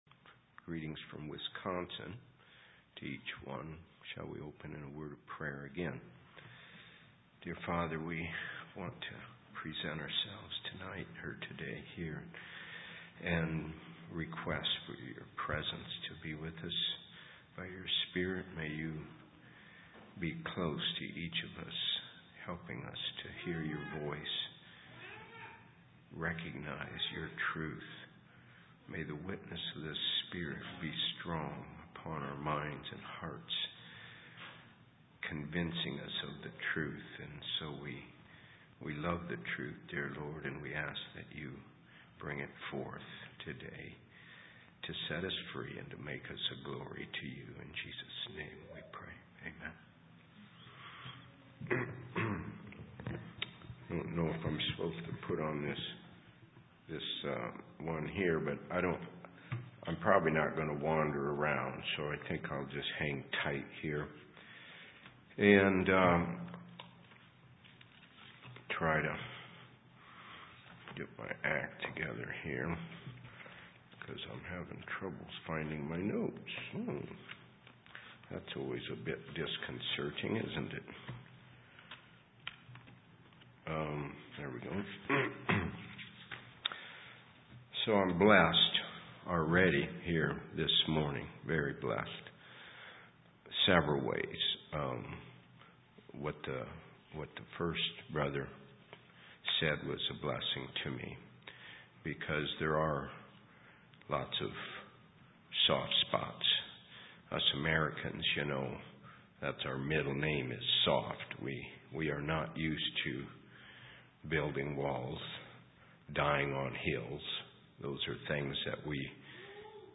2023 Sermons 4/14